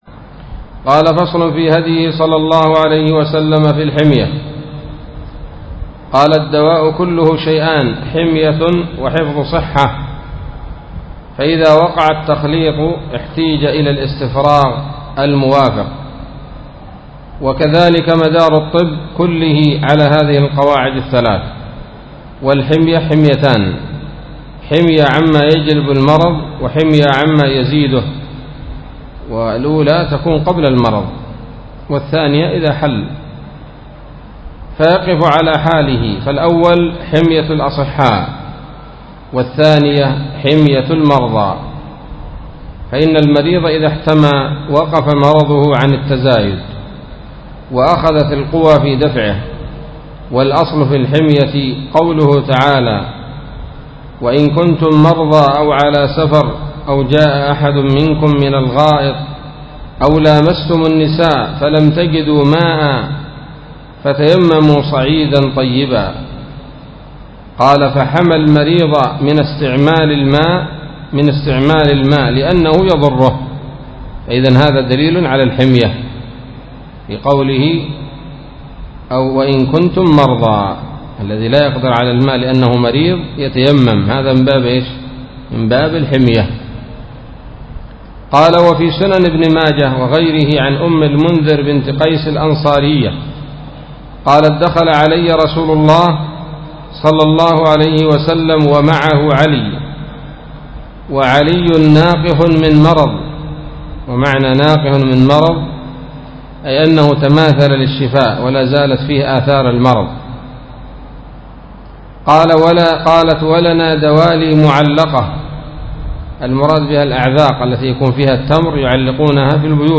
الدرس الثامن والعشرون من كتاب الطب النبوي لابن القيم